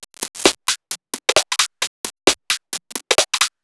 Index of /musicradar/uk-garage-samples/132bpm Lines n Loops/Beats
GA_BeatDCrush132-04.wav